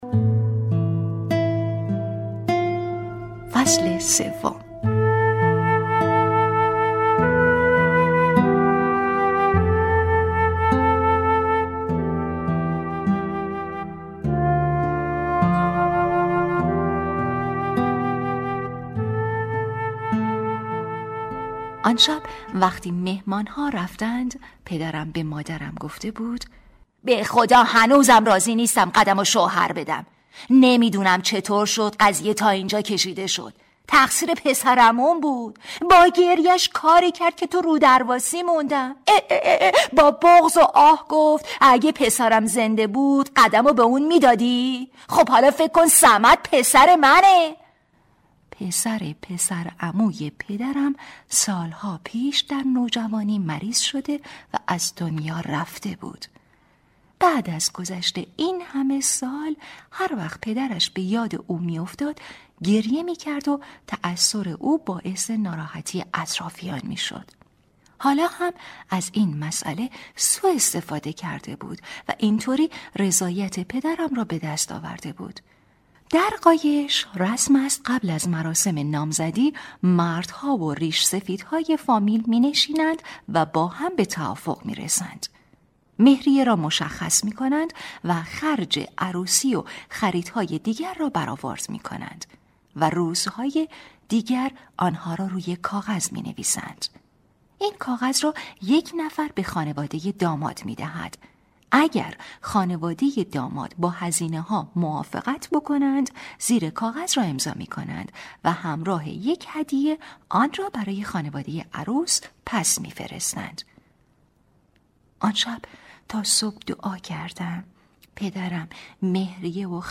کتاب صوتی | دختر شینا (03)